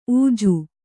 ♪ ūju